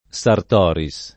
[ S art 0 ri S ]